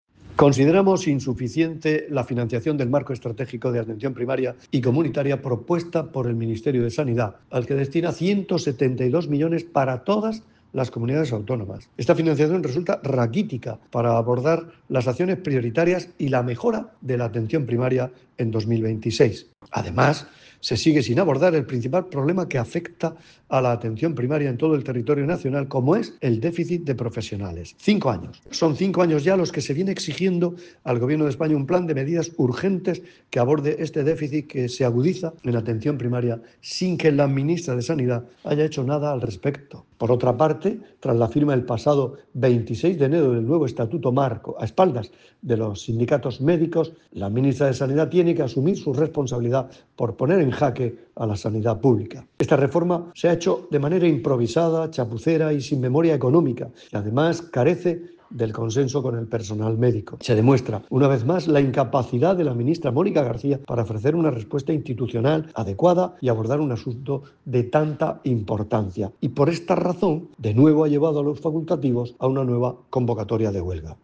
Declaraciones del consejero de Salud, Juan José Pedreño sobre la insuficiente financiación para Atención Primaria.